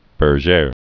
(bər-zhâr)